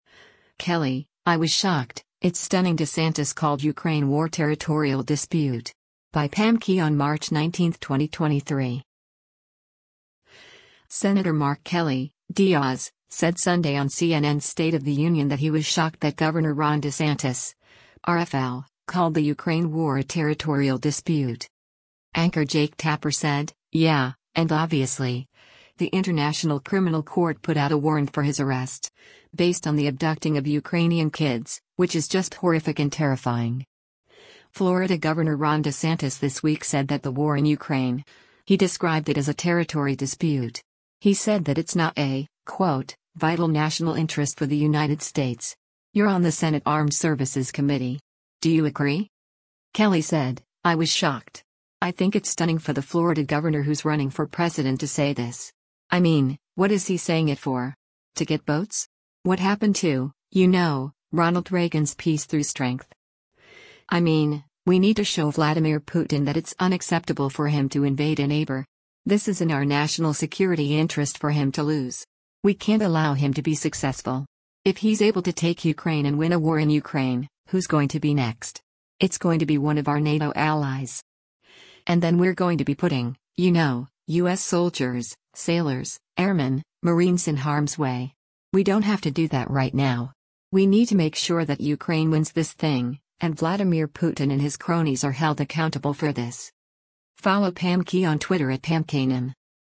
Senator Mark Kelly (D-AZ) said Sunday on CNN’s “State of the Union” that he was shocked that Gov. Ron DeSantis (R-FL) called the Ukraine war a territorial dispute.